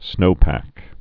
(snōpăk)